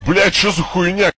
b cho za kh Meme Sound Effect